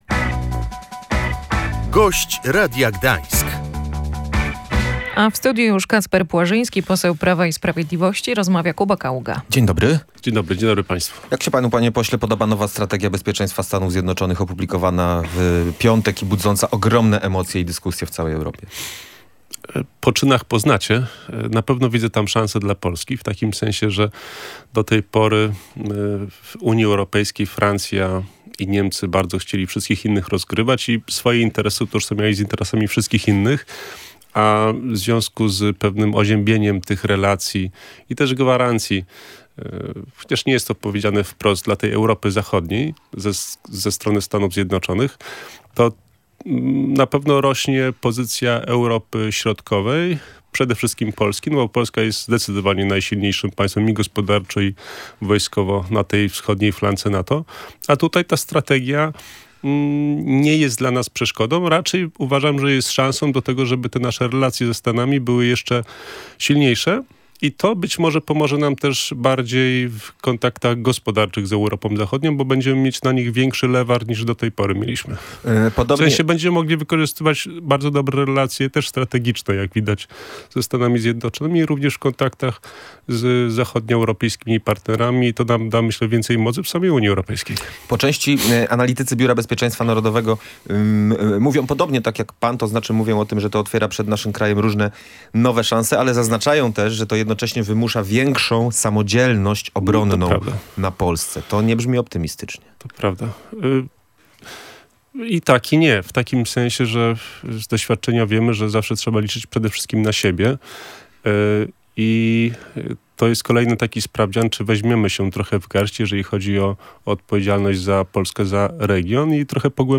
Nowa strategia bezpieczeństwa USA to również szansa dla Polski - uważa poseł Prawa i Sprawiedliwości Kacper Płażyński. "Gość Radia Gdańsk"